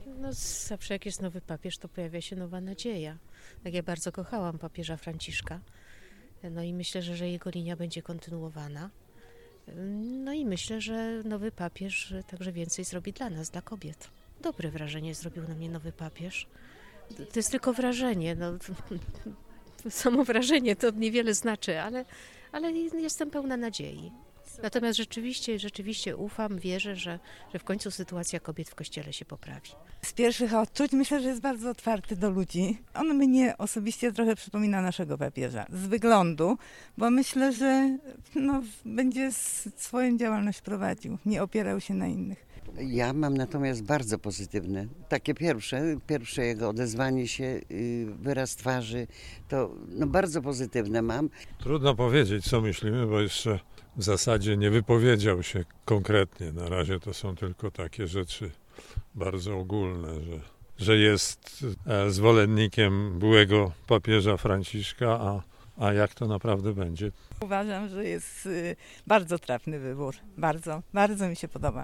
A co o Leonie XIV myślą mieszkanki i mieszkańcy Wrocławia?